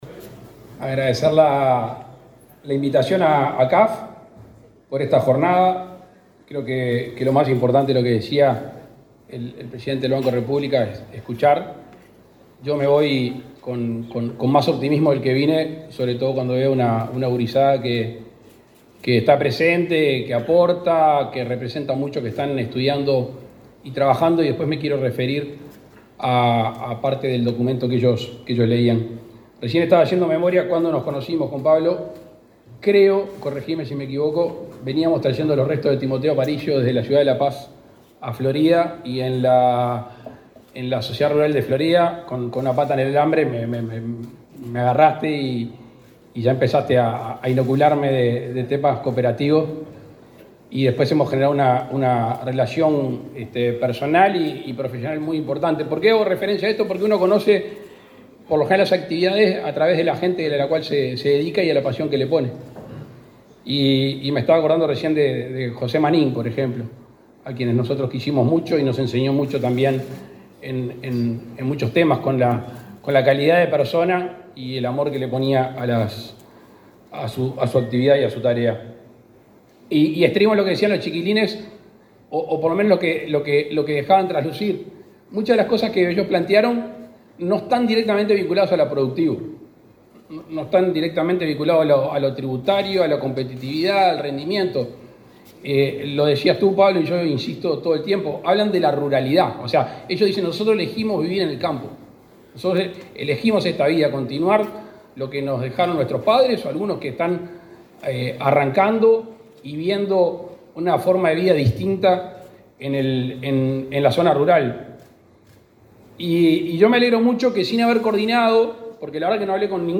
Palabras del presidente Luis Lacalle Pou
El presidente Luis Lacalle Pou asistió a la reunión del Consejo Directivo de fin de año de las Cooperativas Agrarias Federadas, realizada este martes